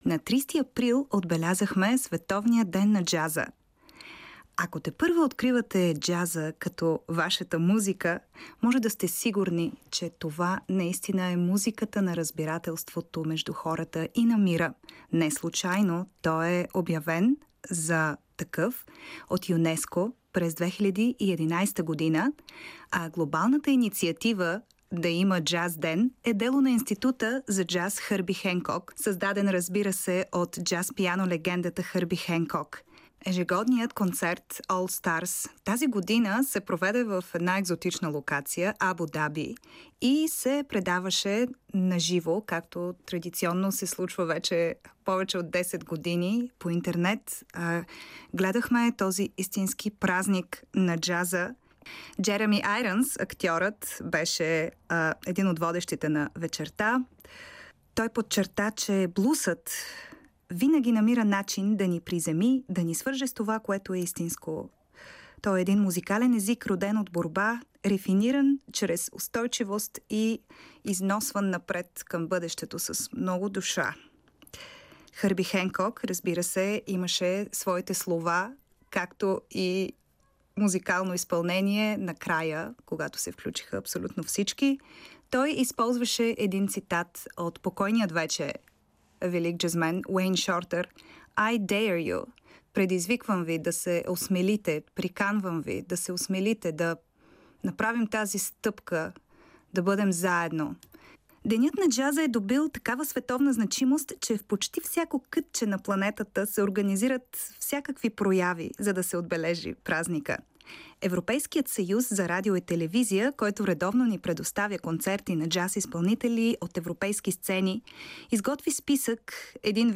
като се наслаждаваме на нов европейски джаз.
Стилистично музиката е разнолика и попада в широк спектър